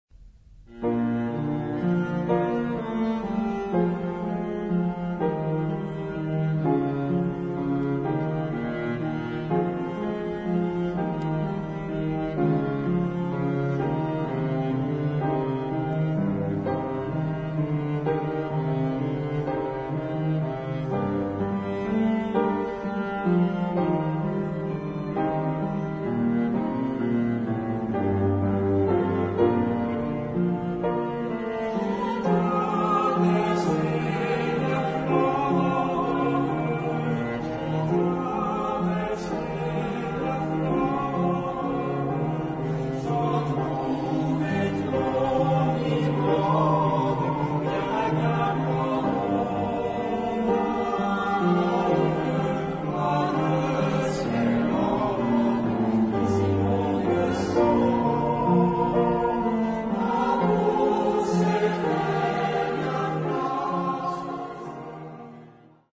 Genre-Style-Form: Popular ; Traditional ; Secular
Type of Choir: AB OR AT OR FH OR SB OR ST  (2 mixed voices )
Instruments: Piano (1) ; Cello (ad lib) ; Guitar (ad lib)
Tonality: B flat major